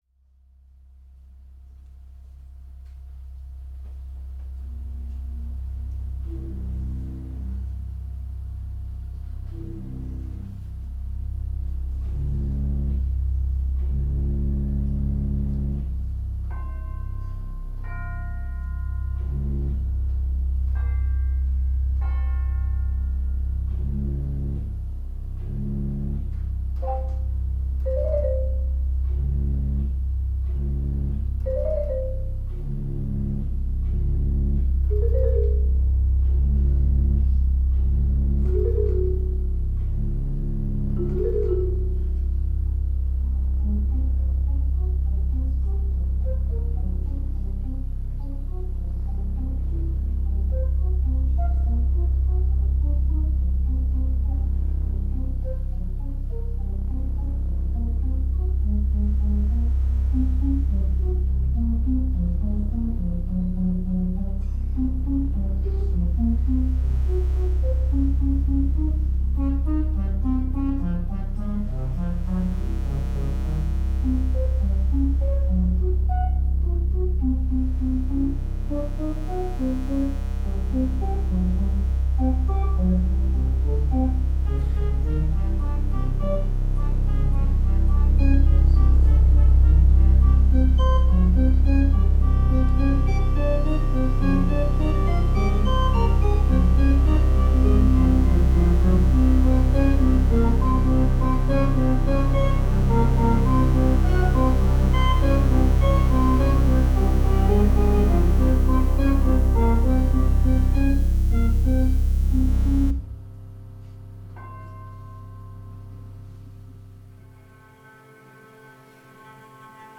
An Evening of Silent Film, Pipe Organ and Dubstep Sounds.